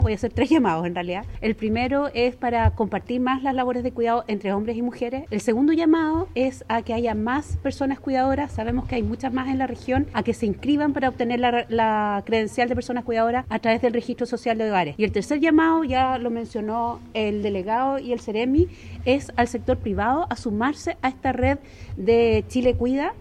Por su parte, la seremi de la Mujer y Equidad de Género en Los Ríos, Francisca Corbalán, hizo tres llamados: uno para compartir las labores de cuidados entre mujeres y hombres, a inscribirse en el Registro de Cuidadores y, en último lugar, al sector privado a sumarse a la red de “Chile Cuida”.